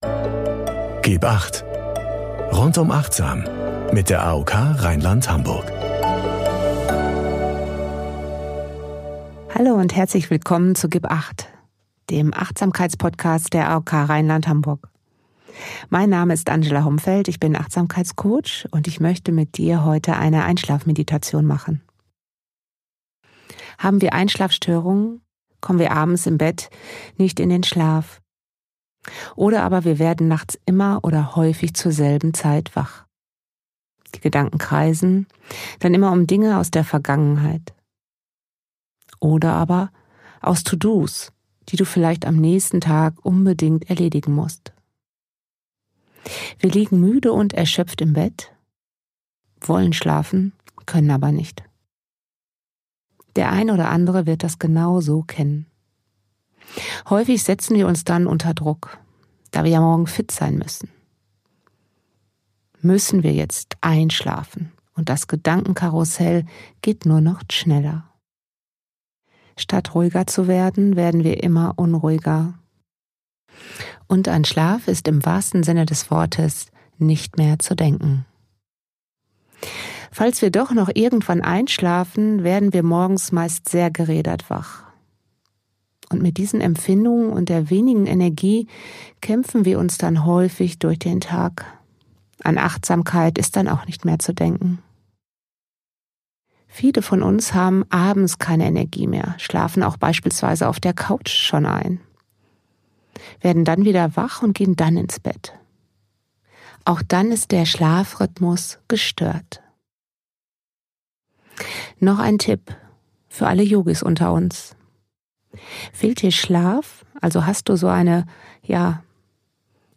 Meditation zum Einschlafen | Geführte Einschlafmeditation ~ gib 8 | Geführte Meditationen und Achtsamkeit Podcast